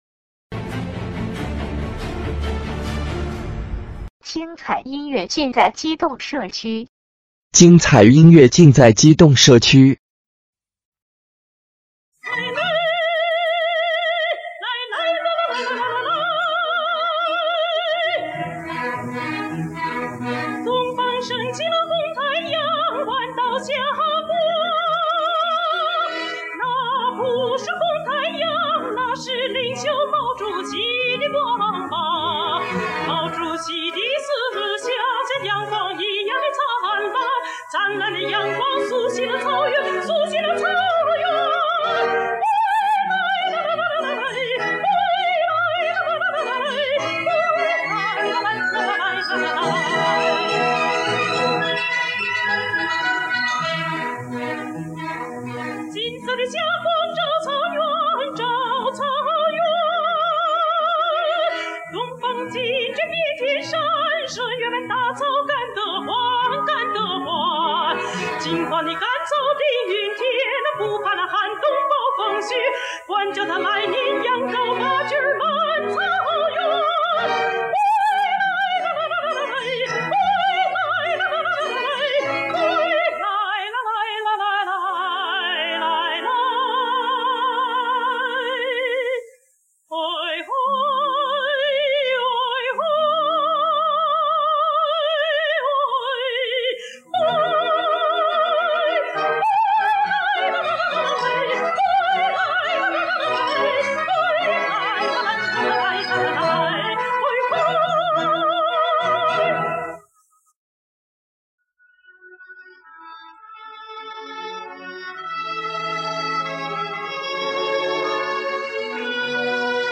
歌剧选段60年代
中外名曲80年代录音